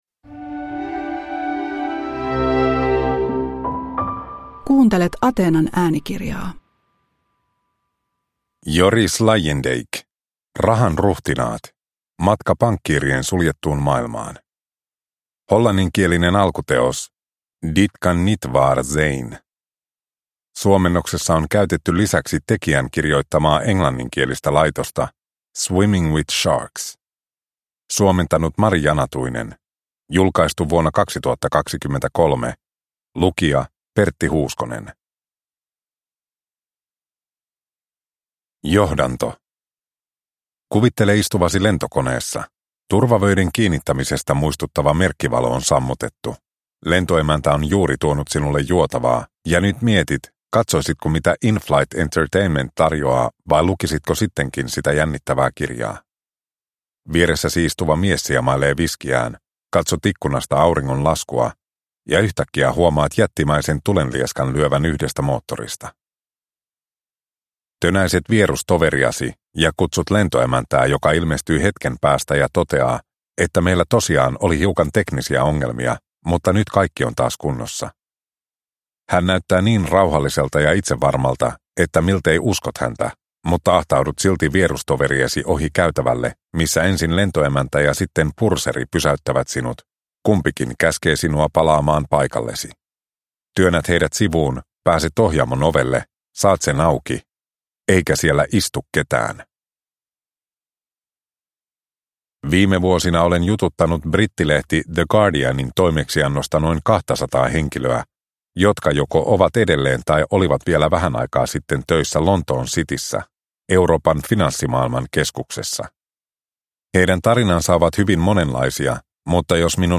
Rahan ruhtinaat – Ljudbok – Laddas ner